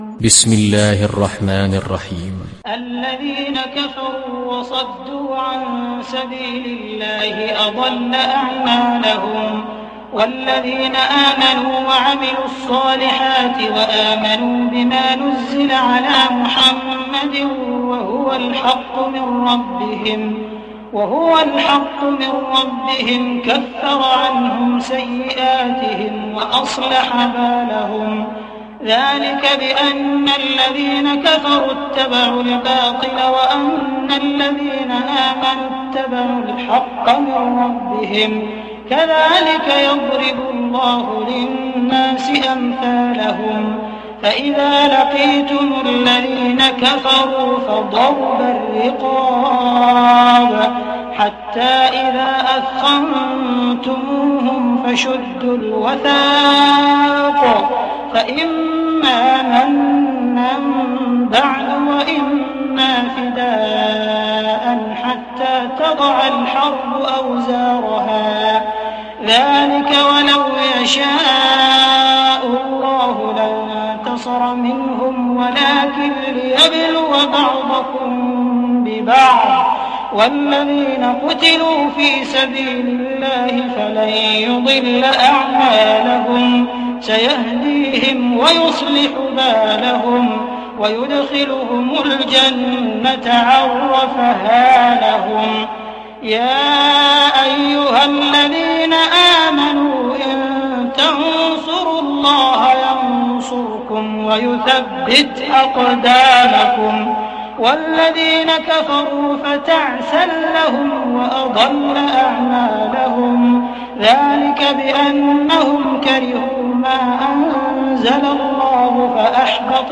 Sourate Muhammad mp3 Télécharger Abdul Rahman Al Sudais (Riwayat Hafs)